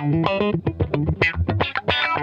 FUNK-E 2.wav